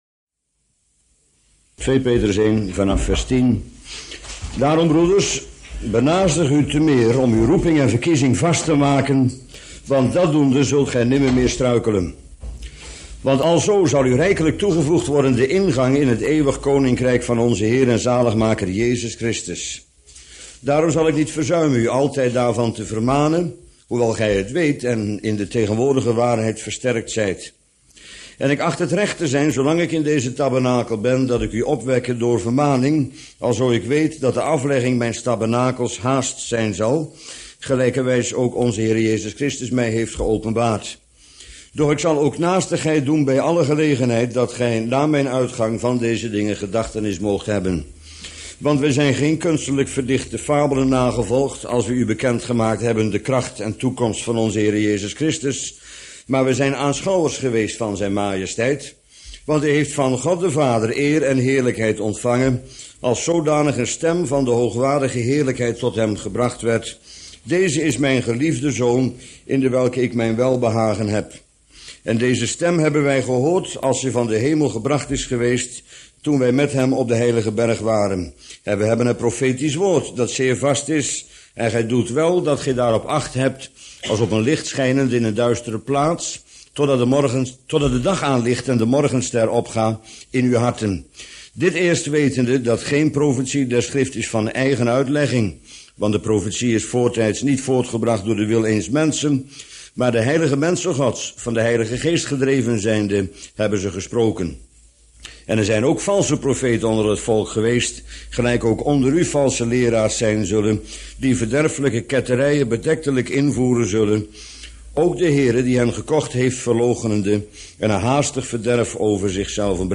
Bijbelstudie